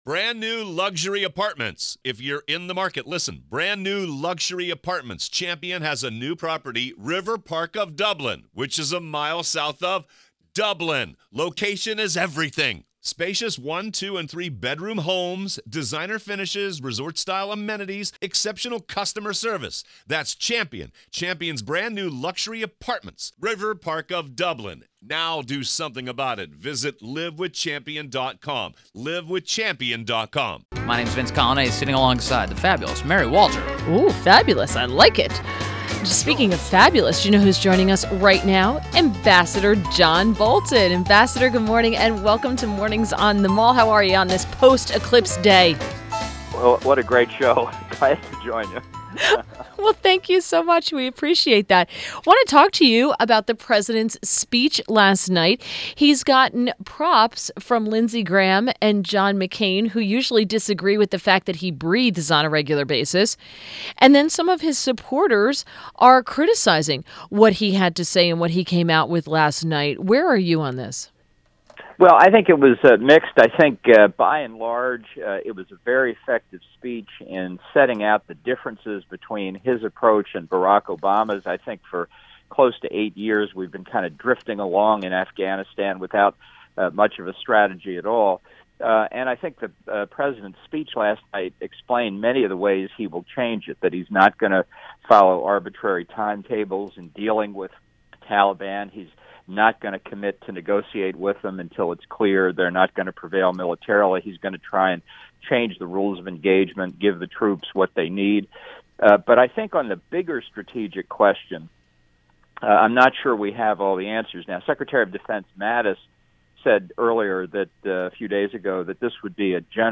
INTERVIEW -- AMB. JOHN BOLTON - Former UN Ambassador